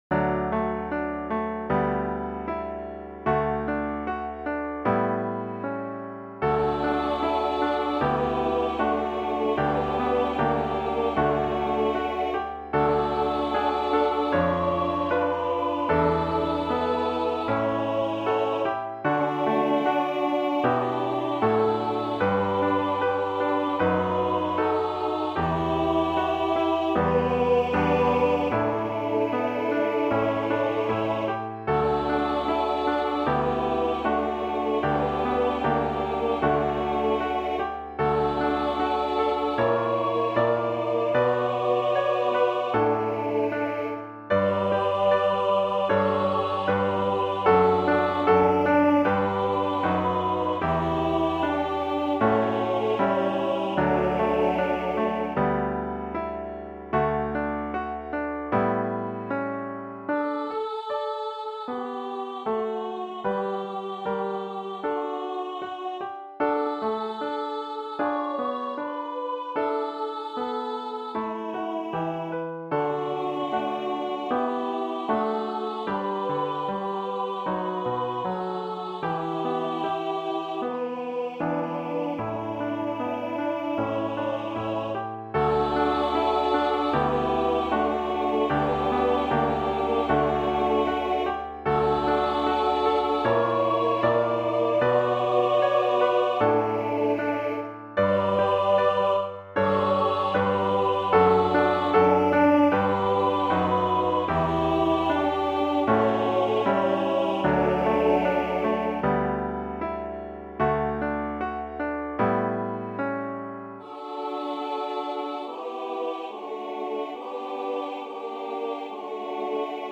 Voicing/Instrumentation: SAA , Trio